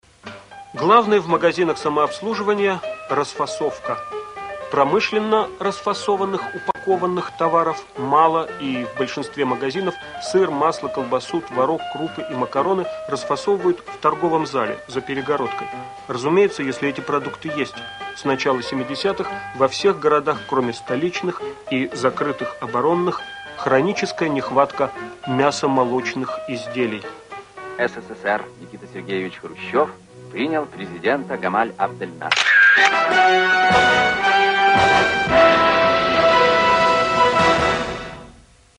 Возможно подражание Баху.
Что-то в духе Баха...